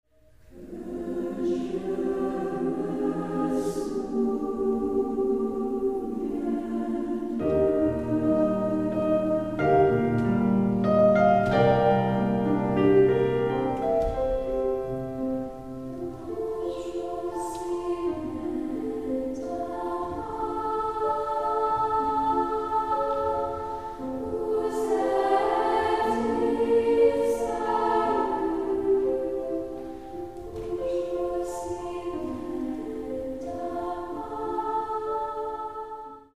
SATB (4 voix mixtes).
Folklore.
Consultable sous : JS-Populaire Francophone avec instruments